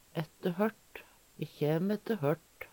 ætte hørt - Numedalsmål (en-US)